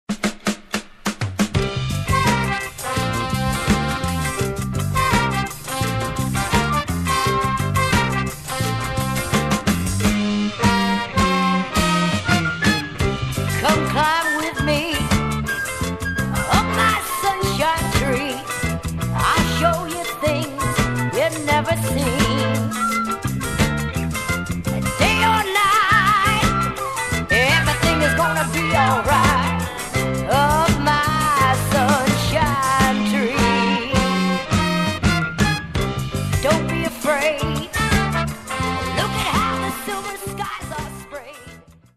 FREE SOUL/RARE GROOVE
ギター